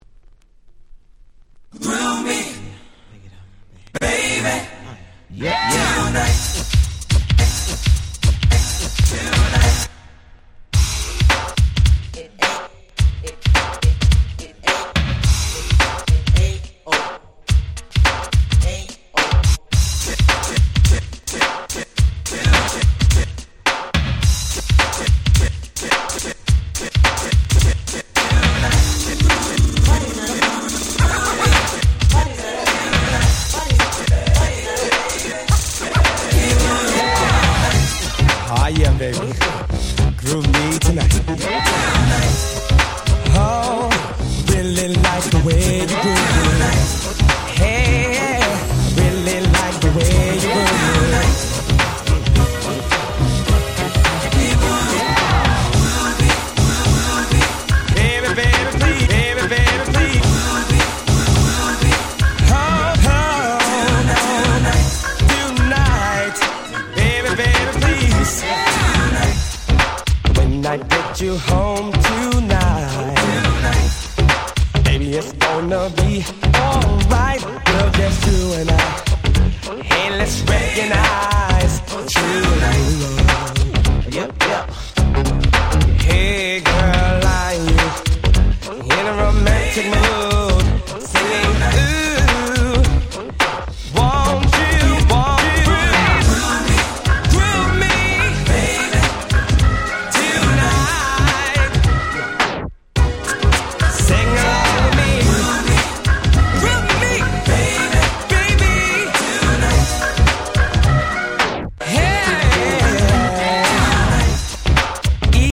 NJS ニュージャックスィング ハネ系 90's